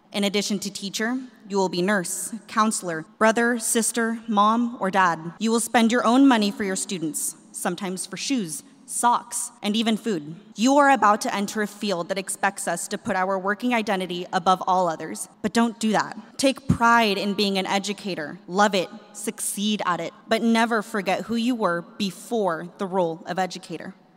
Fall commencement ceremonies were held at Kansas State University over the weekend.